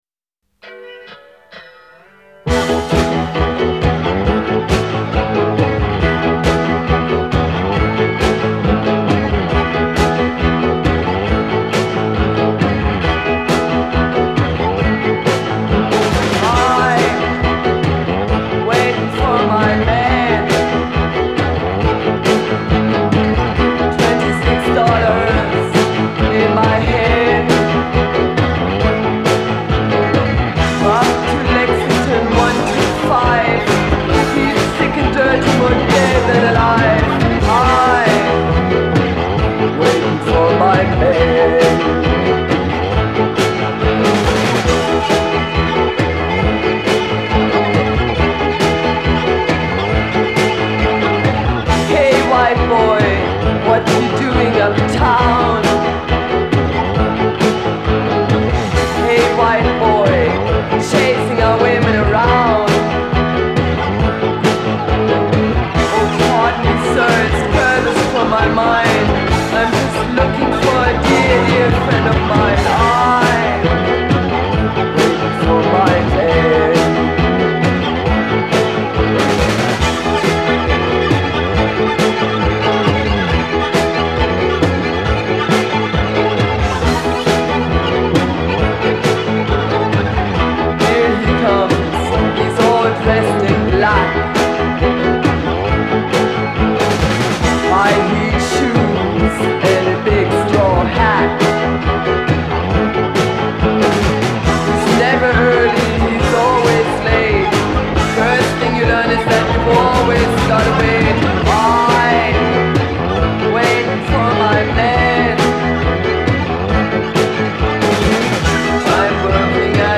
Genre : Punk, New Wave